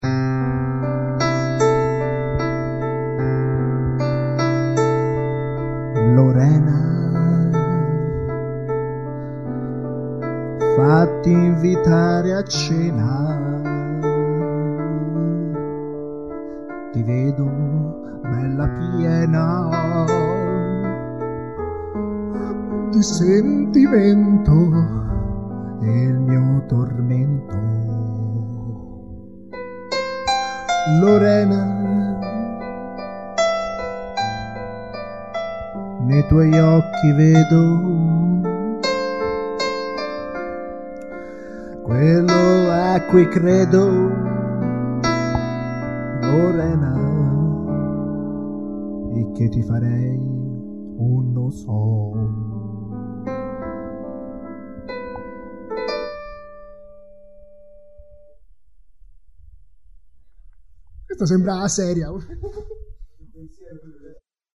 Una breve e romantica canzone